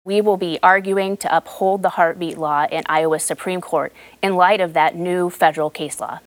Bird, a graduate of the University of Chicago Law School, made her comment during a weekend appearance on “Iowa Press” on Iowa PBS.